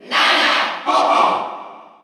File:Ice Climbers Cheer Russian SSBU.ogg
Category: Crowd cheers (SSBU) You cannot overwrite this file.
Ice_Climbers_Cheer_Russian_SSBU.ogg.mp3